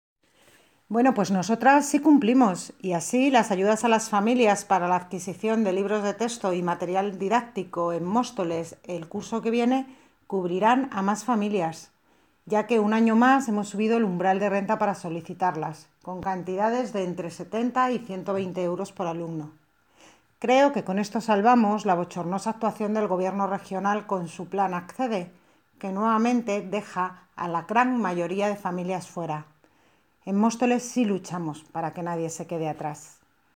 Audio - María Isabel Cruceta (Concejala de Educación) Sobre ayuda para libros de texto